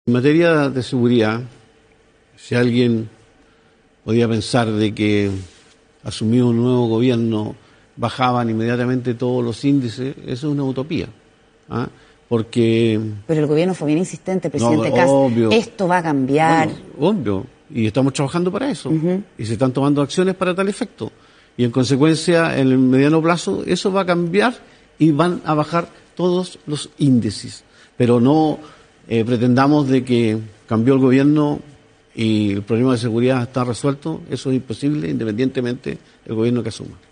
“Si alguien podía pensar que asumió un nuevo gobierno y bajaban inmediatamente todos los índices, eso es una utopía”, afirmó la autoridad durante una entrevista en el programa Estado Nacional.